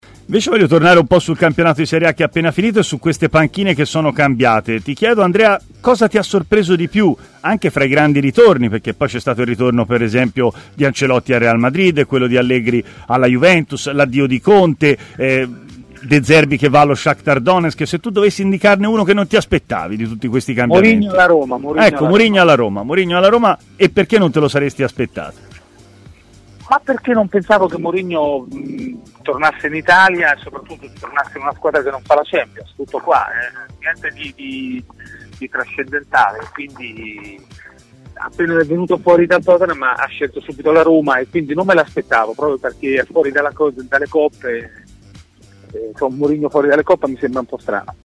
trasmissione di TMW Radio